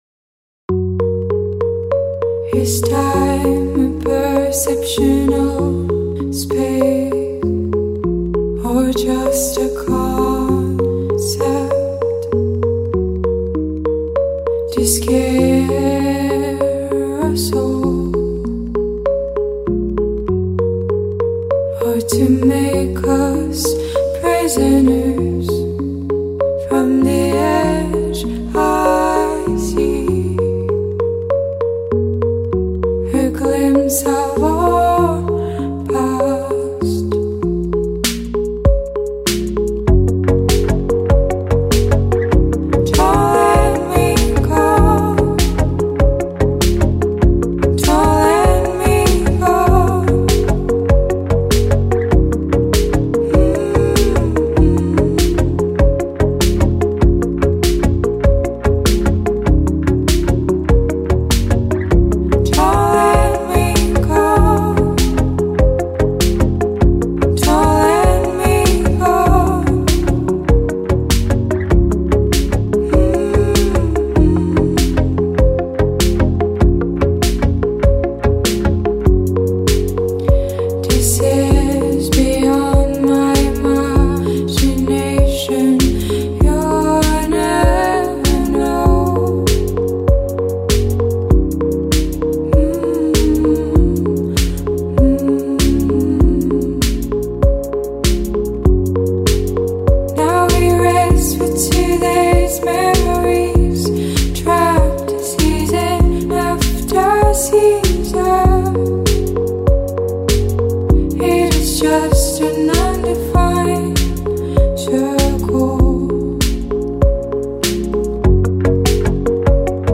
downtempo electronic pop duo
beautiful and haunting track